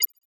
Holographic UI Sounds 99.wav